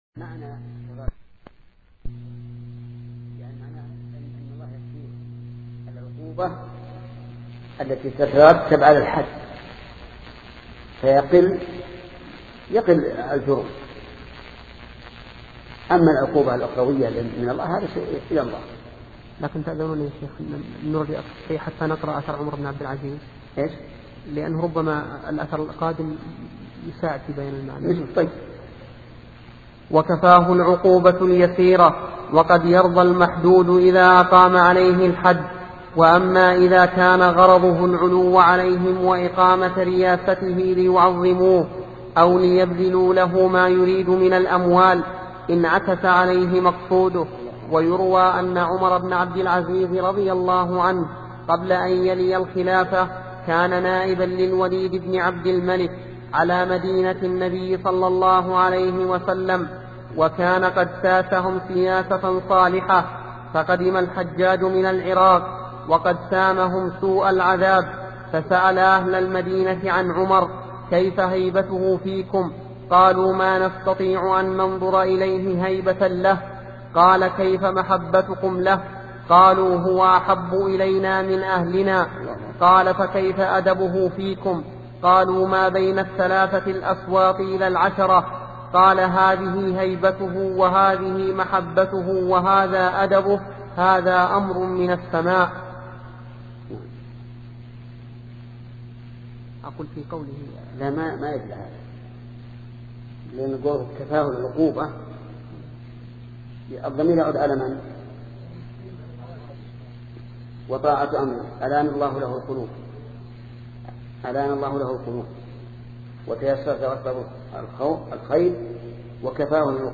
سلسلة مجموعة محاضرات التعليق على السياسة الشرعية لابن تيمية لشيخ محمد بن صالح العثيمين رحمة الله تعالى